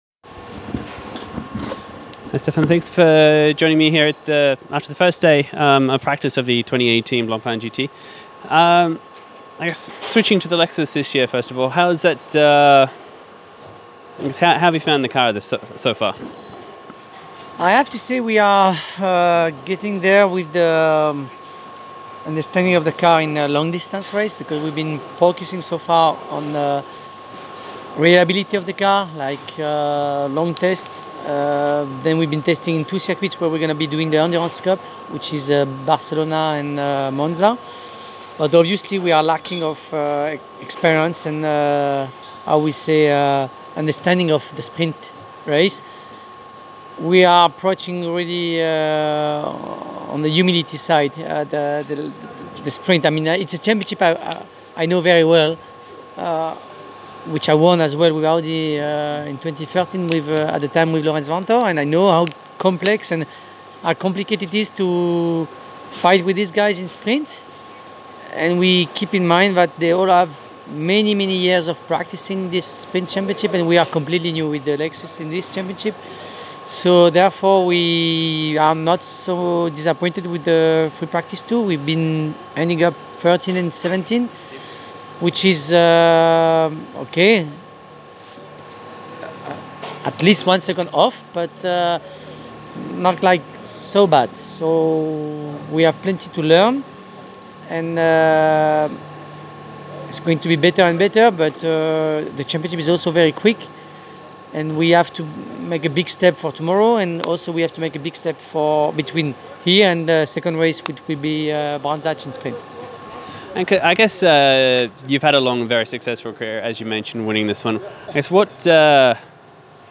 In this interview Ortelli discusses the development of the team, what they have done to get the car to this point and more.